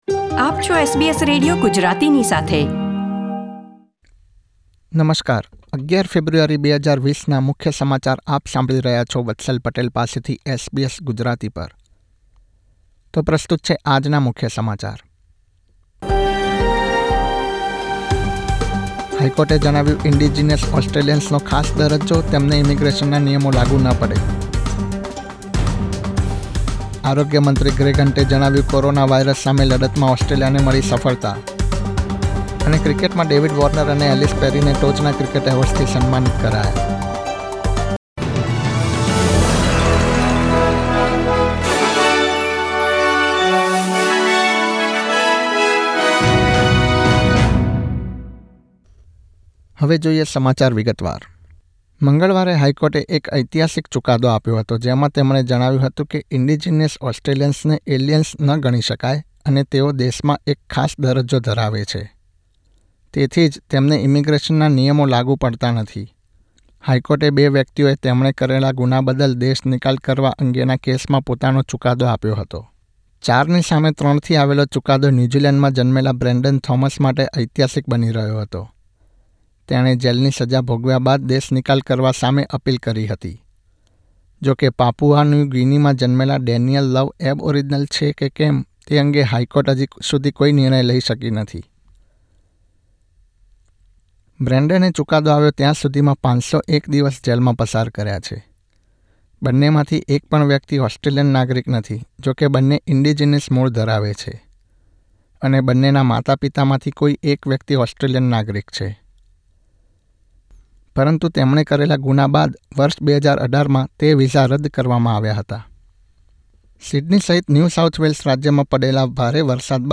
SBS Gujarati News Bulletin 11 February 2020